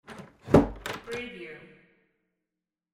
Door Close Wav Sound Effect #11B
Description: Wooden room door pulled/pushed close
Properties: 48.000 kHz 24-bit Stereo
Keywords: door, close, closing, pull, pulling, push, pushing, shut, shutting, house, apartment, office, room, wood, wooden
door-11-close-preview-2.mp3